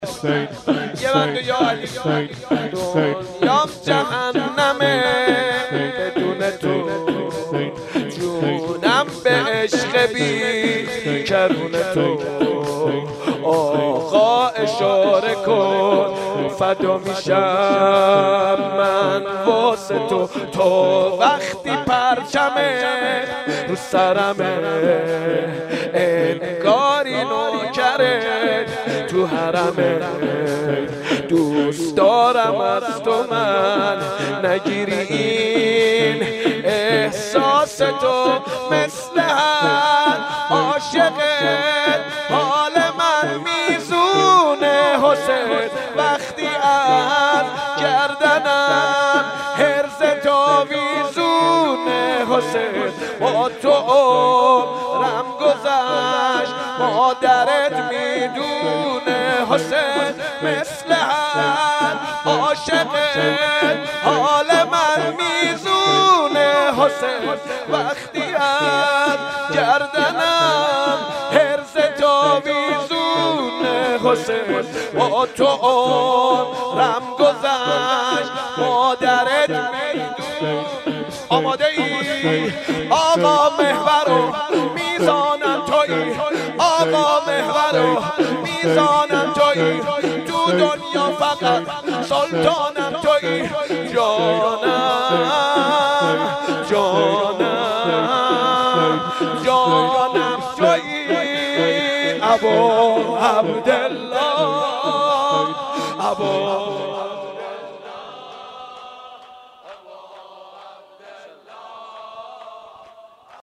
شب سوم محرم 1440
شور